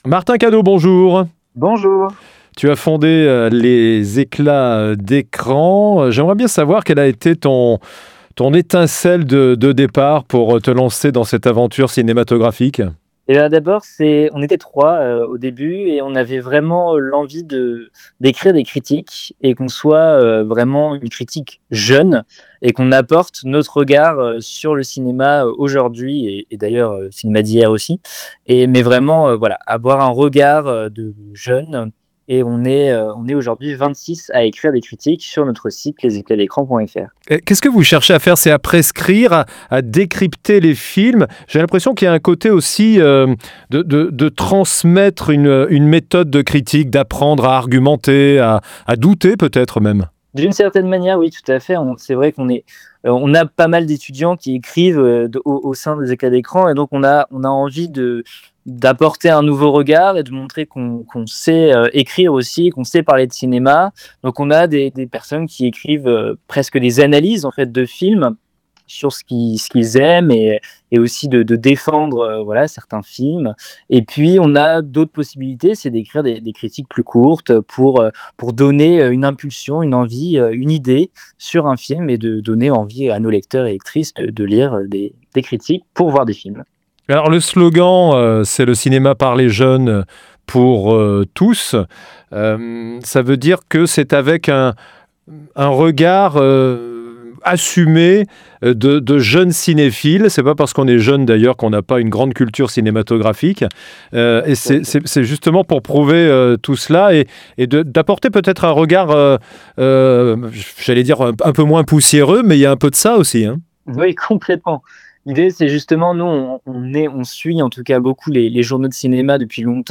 2. Podcasts cinéma : interviews | La Radio du Cinéma